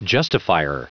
Prononciation du mot justifier en anglais (fichier audio)
Prononciation du mot : justifier